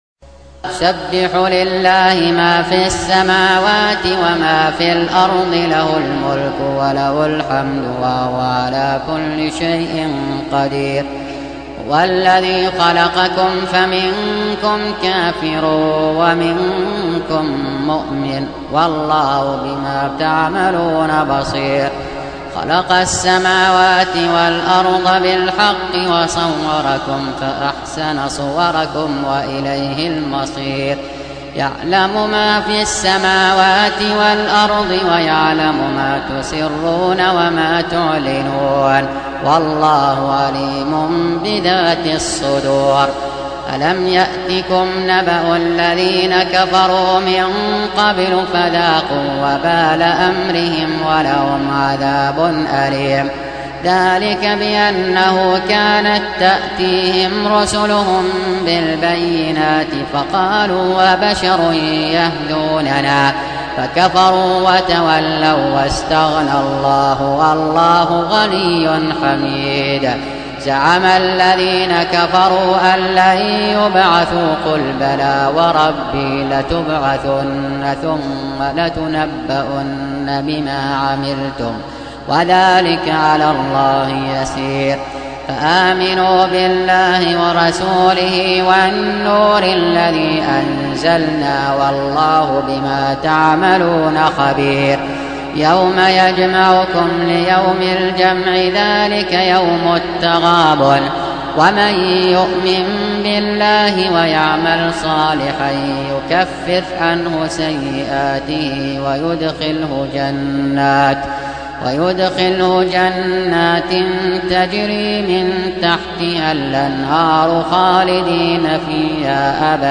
Surah Sequence تتابع السورة Download Surah حمّل السورة Reciting Murattalah Audio for 64. Surah At-Tagh�bun سورة التغابن N.B *Surah Includes Al-Basmalah Reciters Sequents تتابع التلاوات Reciters Repeats تكرار التلاوات